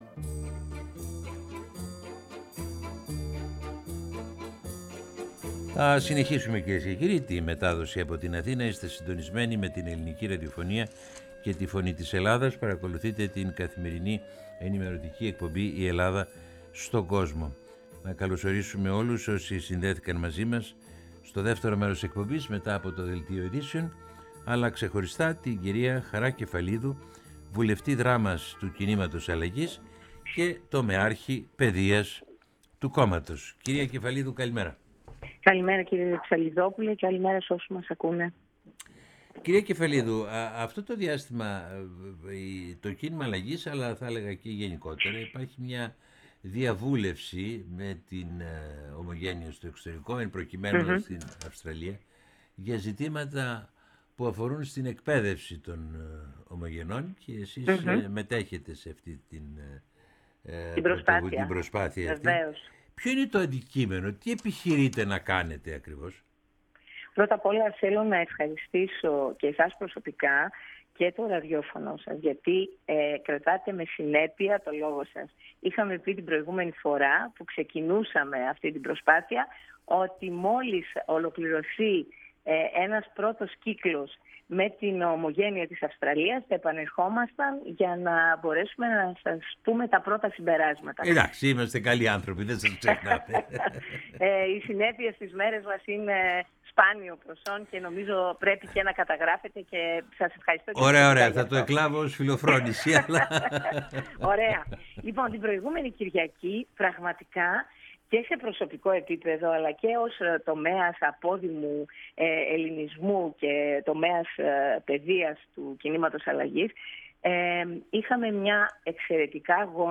δήλωσε, μιλώντας στη Φωνή της Ελλάδας και στην εκπομπή «Η Ελλάδα στον κόσμο»